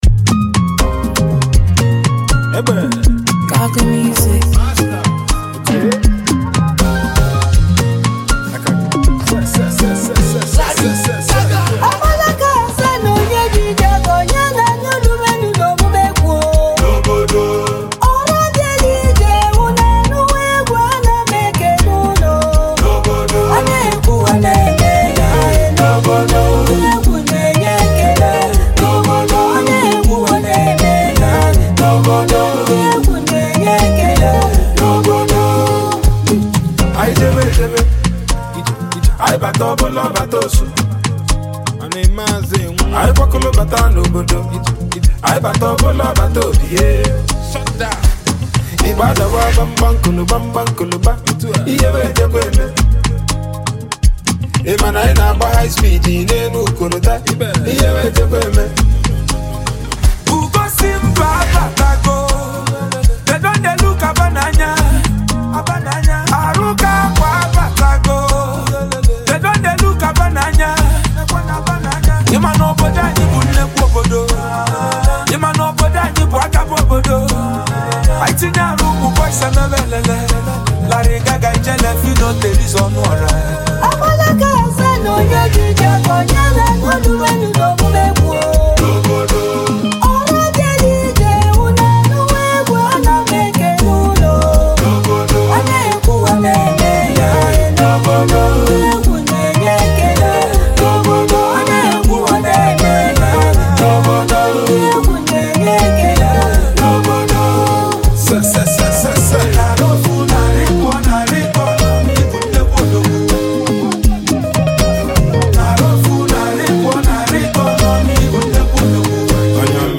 a gifted indigenous female tradiional vocalist and icon